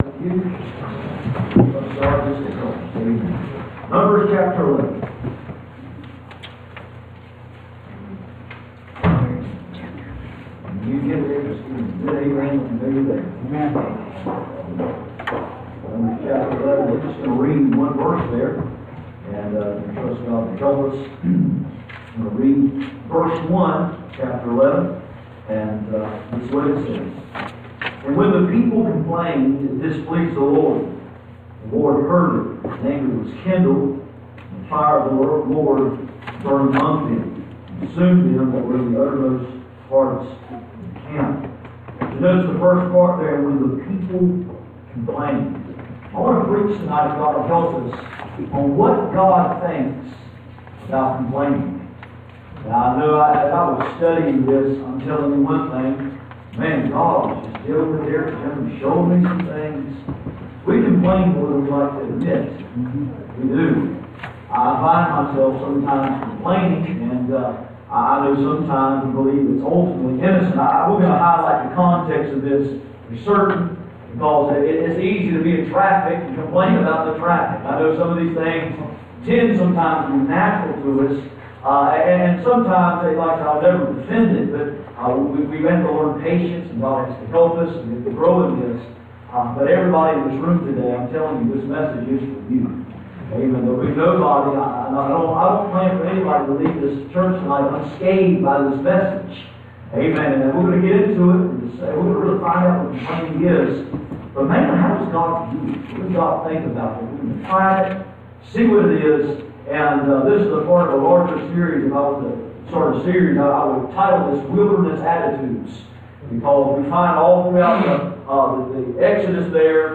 Numbers 11:1 Service Type: Sunday Evening %todo_render% « Spirituals Hitchhikers Asaphs problem stated